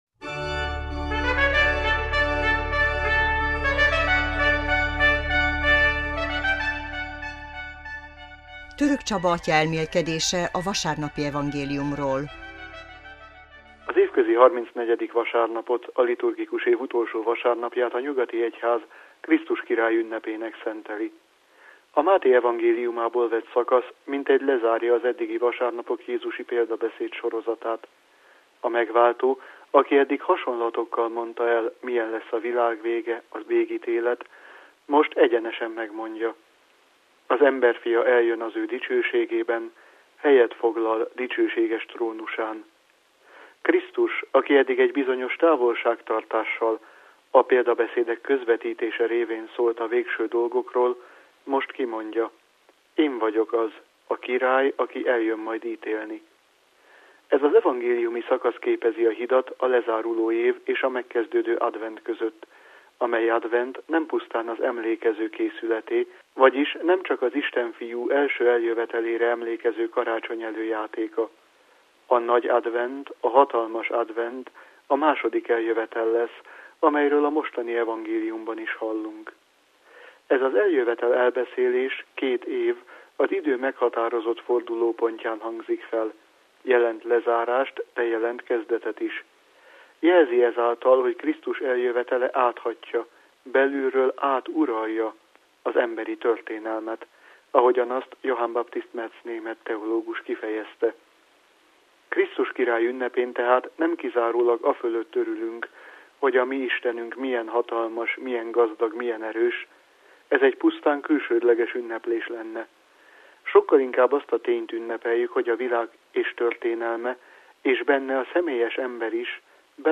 elmélkedése a vasárnapi evangéliumról